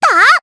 May-Vox_Attack3_jp.wav